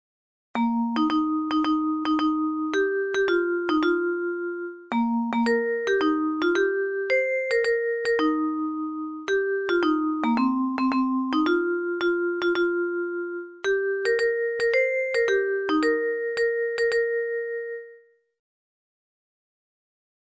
ﾋﾞﾌﾞﾗﾌｫﾝ